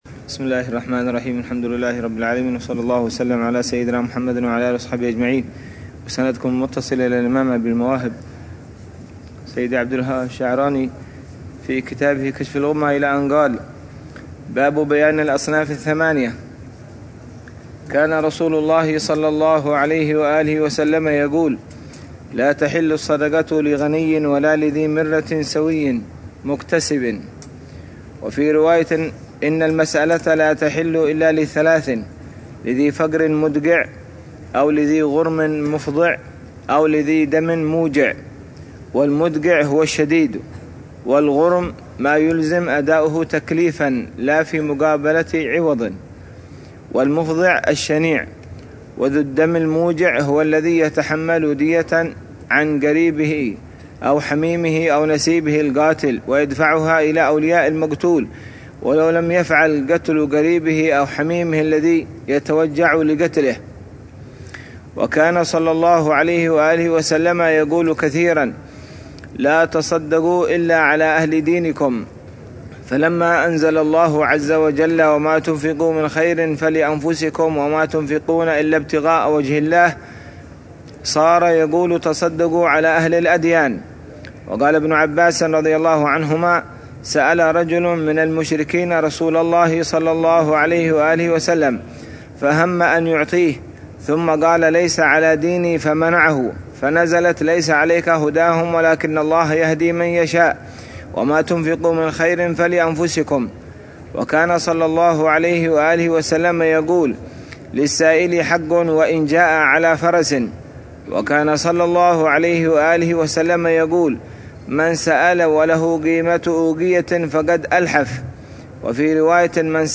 يواصل في الدرس شرح أحكام الزكاة، ويوضح مسائل مهمة عن الفئات الثمانية المستحقة لها